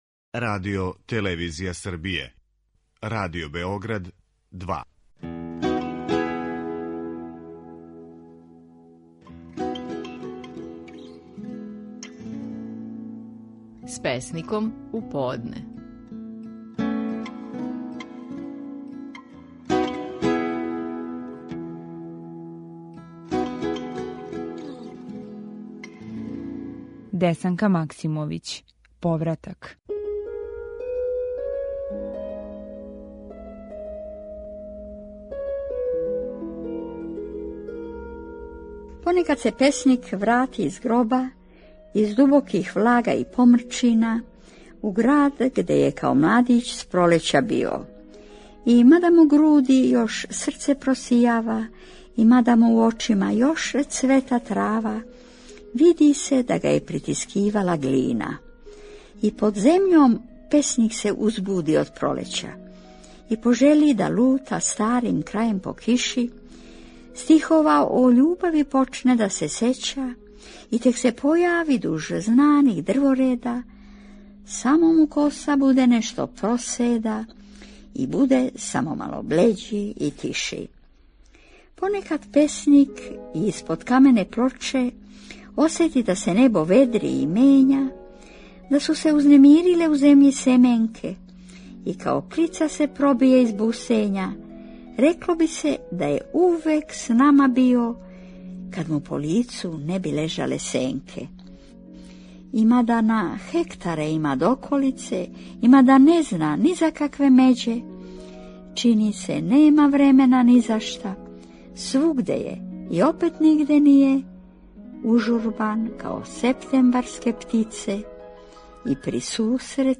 Стихови наших најпознатијих песника, у интерпретацији аутора.
Десанка Максимовић говори своју песму: „Повратак".